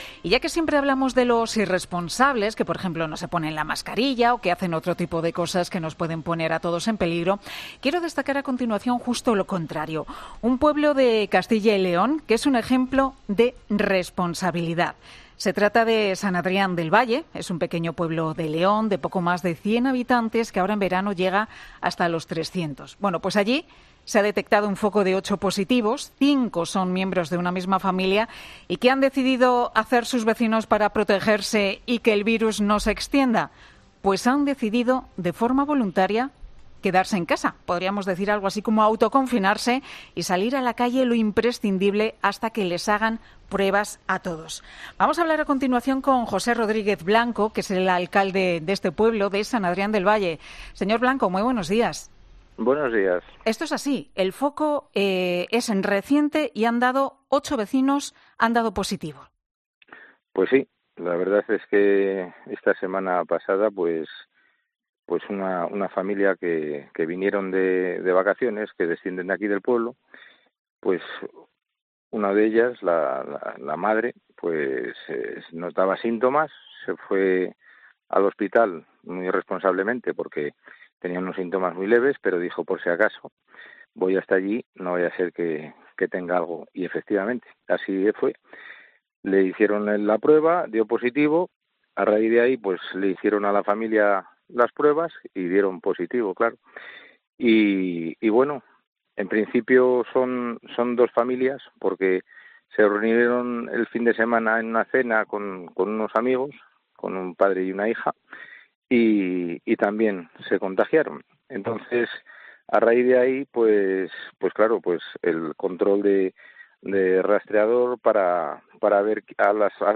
José Rodríguez Blanco, alcalde de San Adrián del Vall